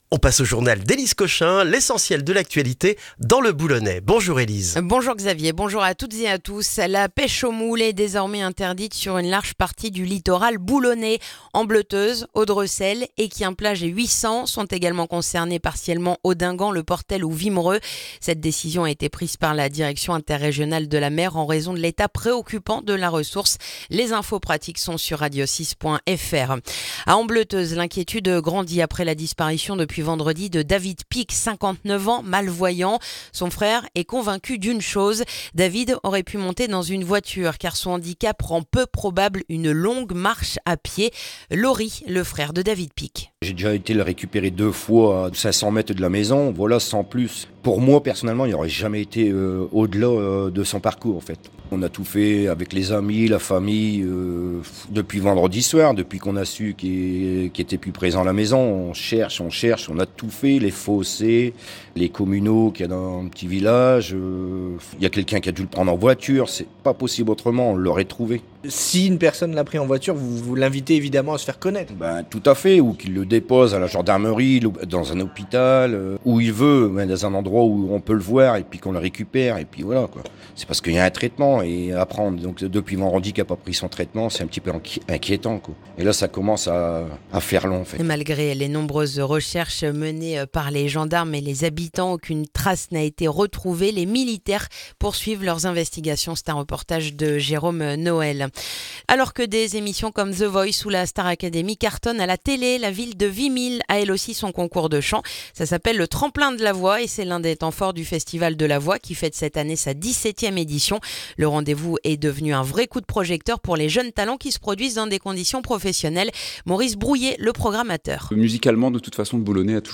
Le journal du mercredi 21 janvier dans le boulonnais